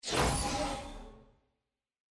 UI_Roundswitch02.mp3